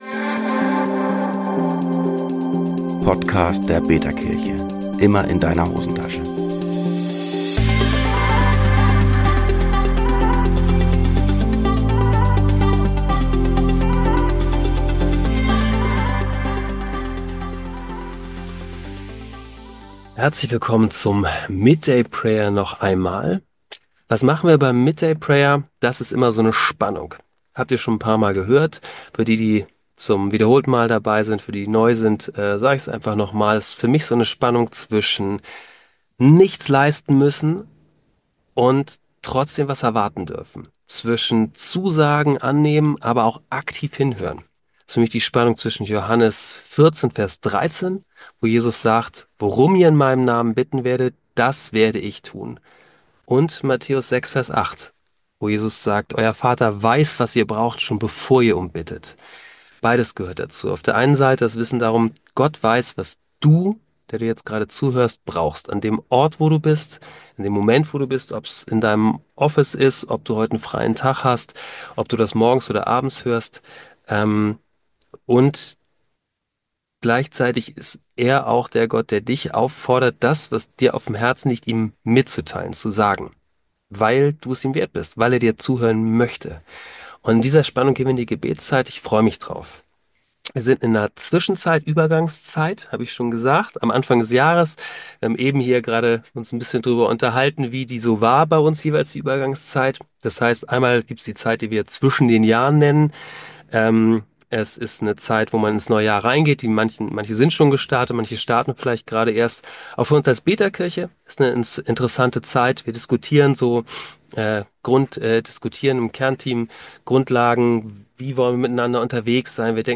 Dieses mal staunen mit einer Gruppe von Freundinnen und Freunden vom Jesus gen Himmel.